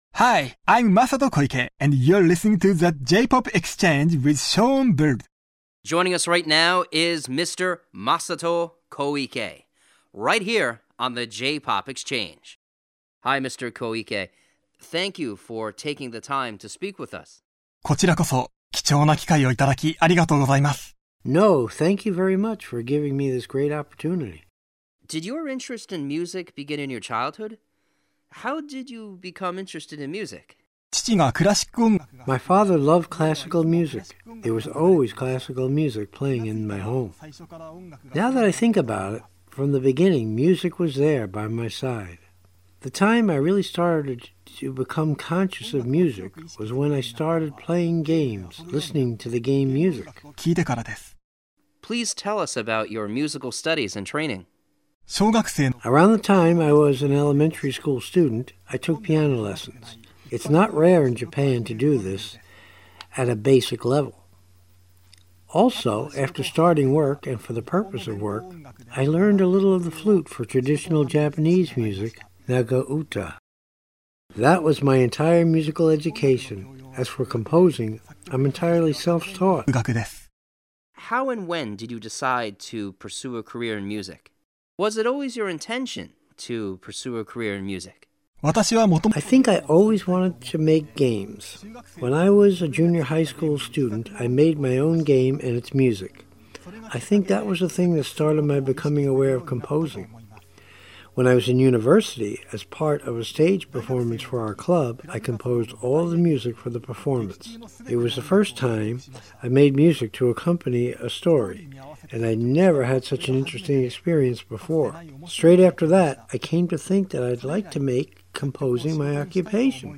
Listen to an excerpt of Radio Interview w/o Translation Voice-Over (Web Exclusive)